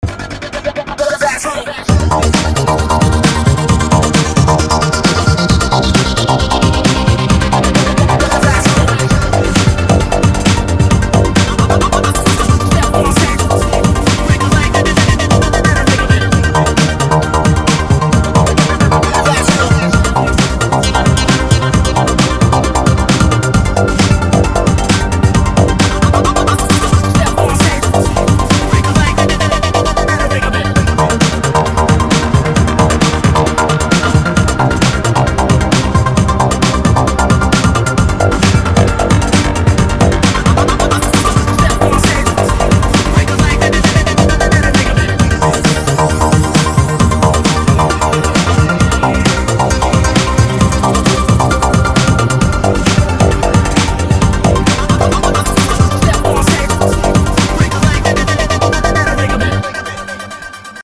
• Качество: 320, Stereo
мужской голос
Electronic
EDM
drum n bass
breakbeat
Стиль: drum&bass + electro